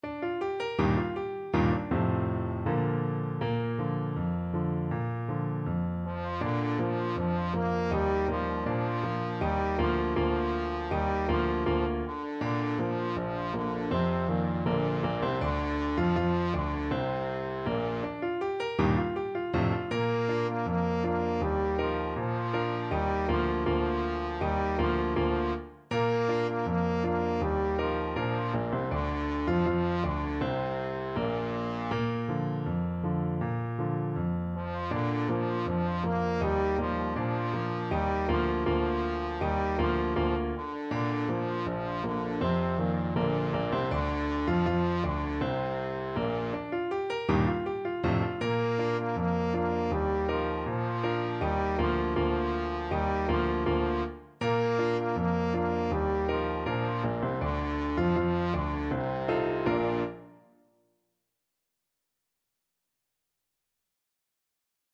2/2 (View more 2/2 Music)
With gusto = c.80